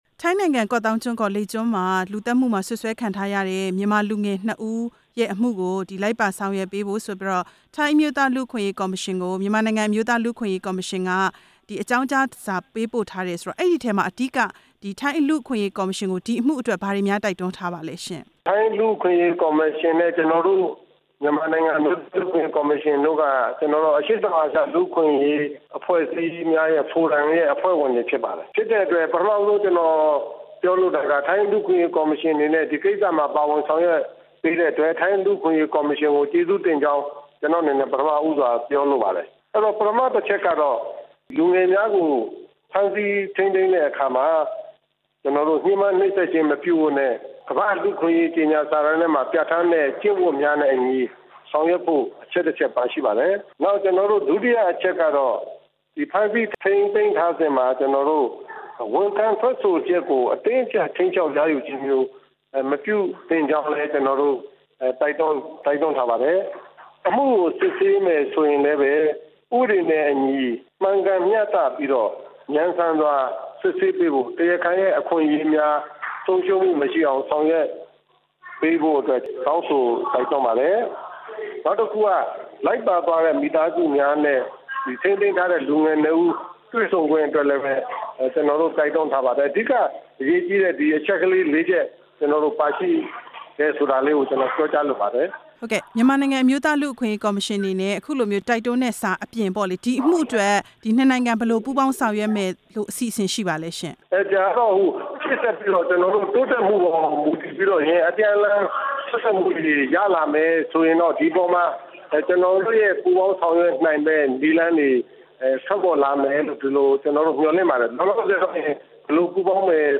ဦးစစ်မြိုင်ကို မေးမြန်းချက်